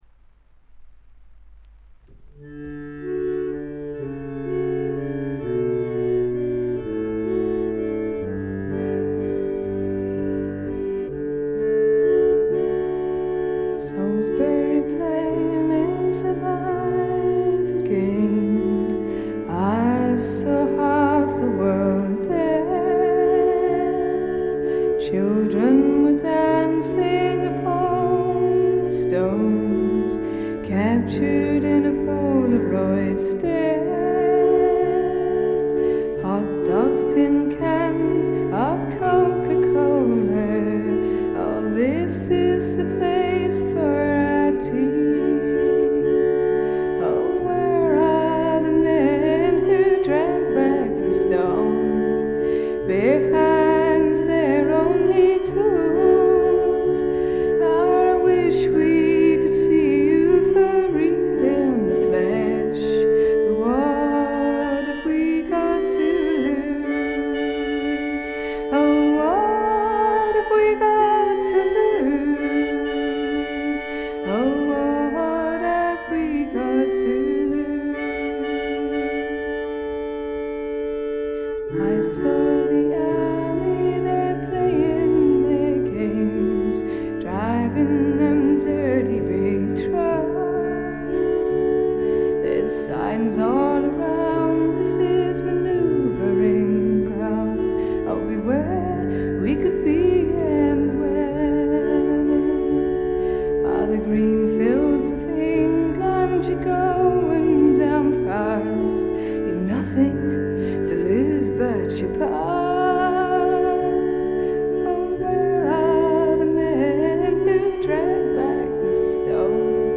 試聴曲は現品からの取り込み音源です。
recorder, bongoes
cello